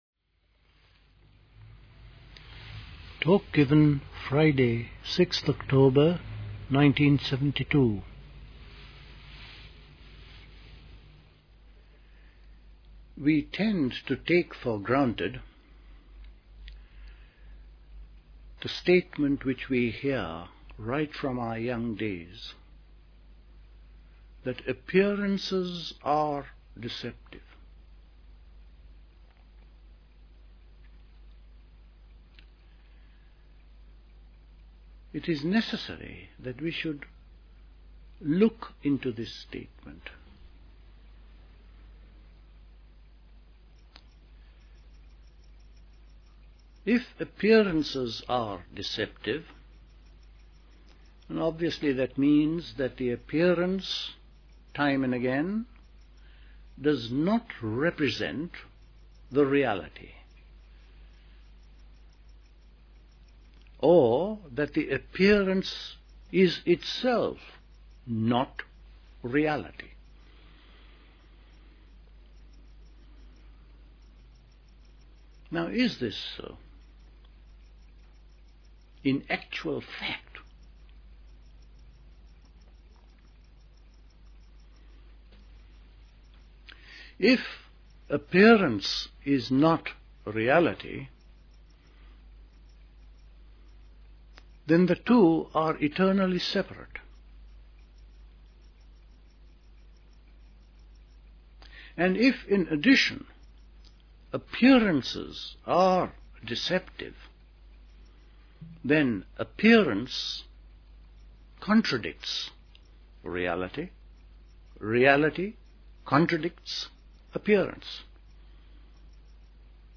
A talk
at Dilkusha, Forest Hill, London on 6th October 1972